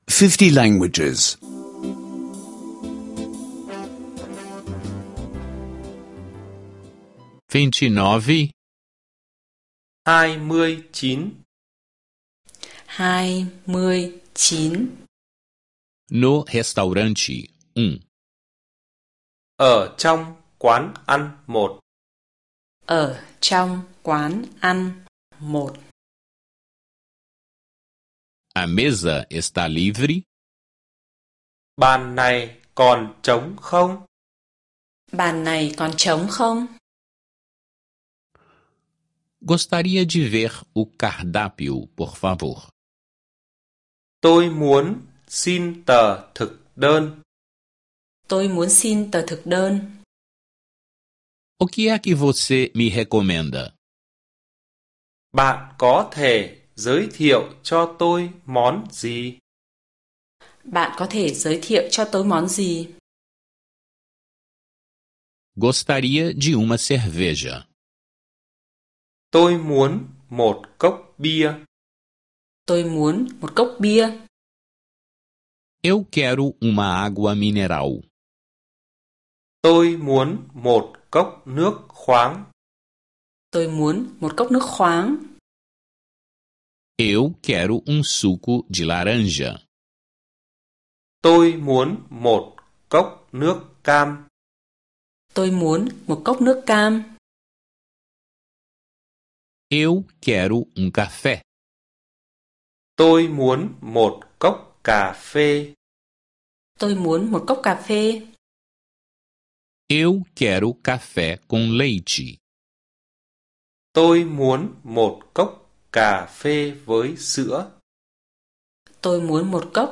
Aulas de vietnamita em áudio — escute online